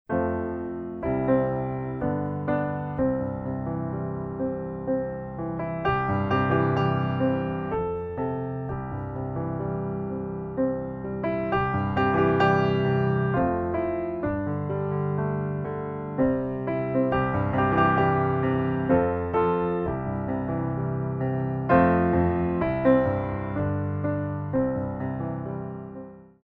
By Pianist & Ballet Accompanist
Piano selections include: